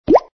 knifeAttackSun.mp3